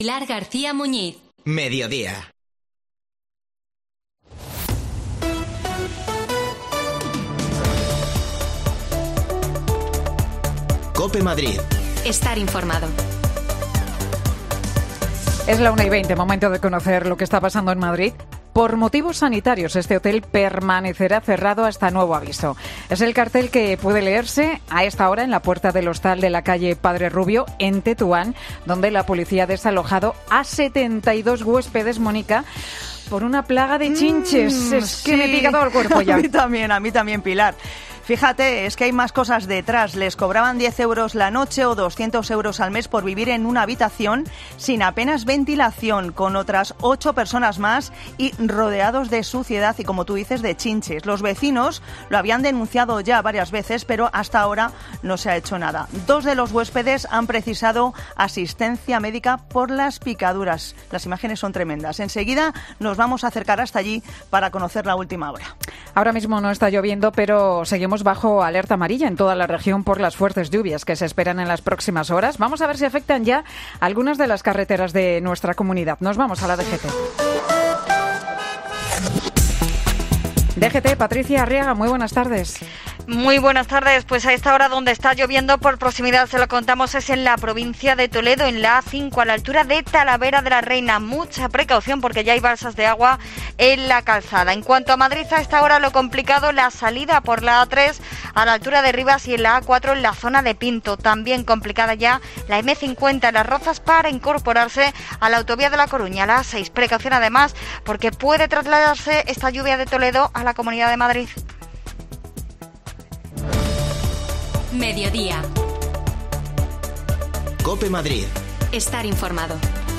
AUDIO: 71 personas han tenido que ser desalojadas de un hostal de Tetuán por una plaga de chinches. Te lo contamos desde alli, en la calle Padre Rubio